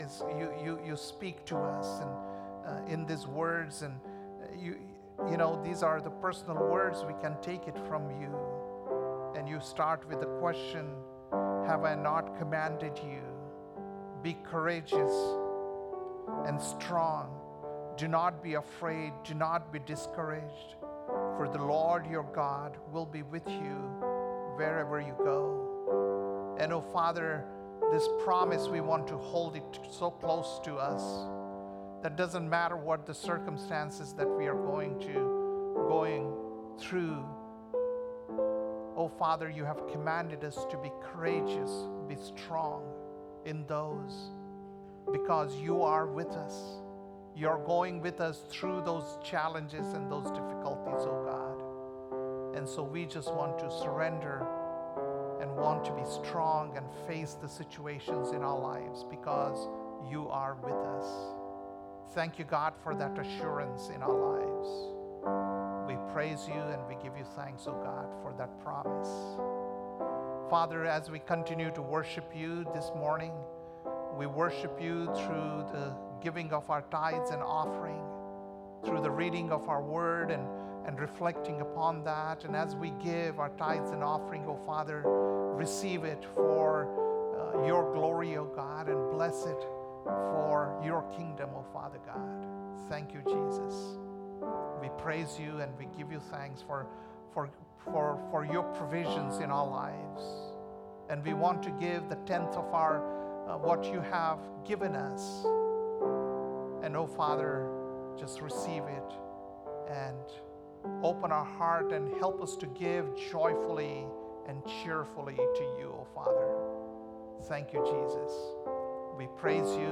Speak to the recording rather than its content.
March 2nd, 2025 - Sunday Service - Wasilla Lake Church